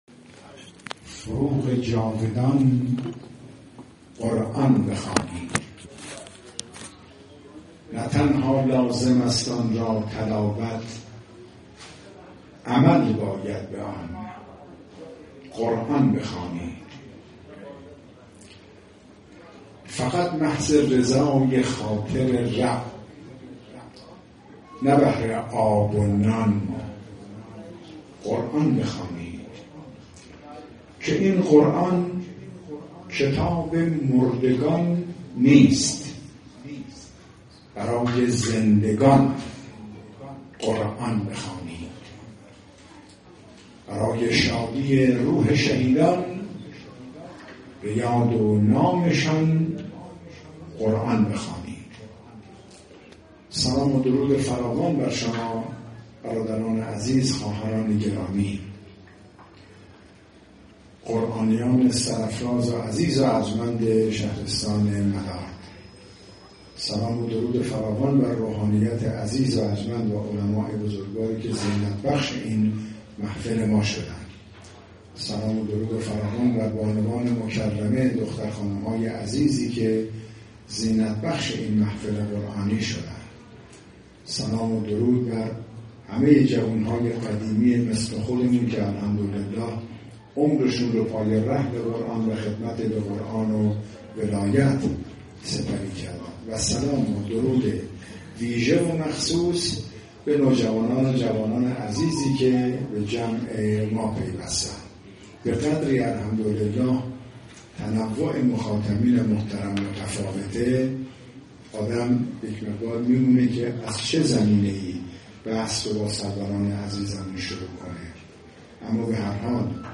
پیشکسوت و قاری بین‌المللی کشورمان در مراسم تجلیل از معلمان و قاریان قرآن شهرستان ملارد، ضمن پیشنهاد برای تأسیس دارالقرآن گلزار شهدای ملارد، رونق‌بخشی به جلسات آموزش قرآن و محافل قرآن و عترت را وظیفه تمامی مسلمانان برشمرد و آن را عامل محکومیت اهانت‌کنندگان و در نهایت نتیجه چنین عملی را رفع معضلات اجتماعی دانست.